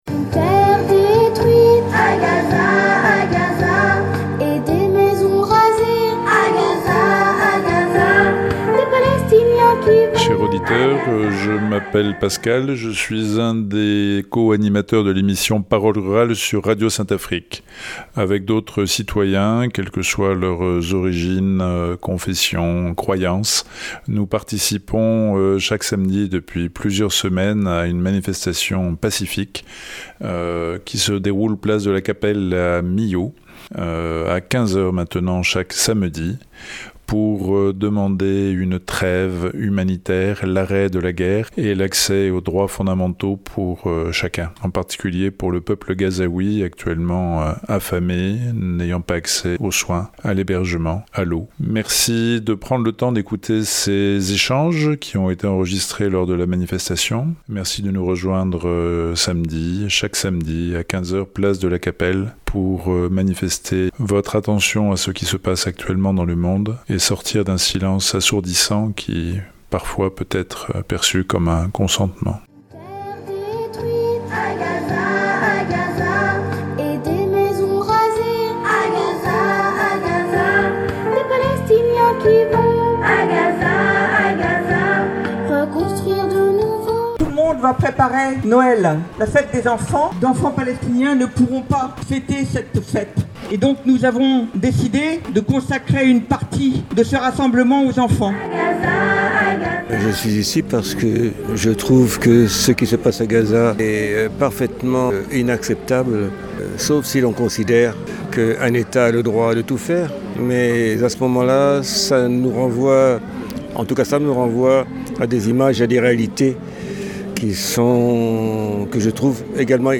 Freestyle intempestif – Micro-trottoir lors d'une manifestation de soutien à la Palestine – 19 décembre 2023 - Radio Larzac
Cette semaine, dans notre créneau ‘Freestyle intempestif » nous vous proposons un micro-trottoir.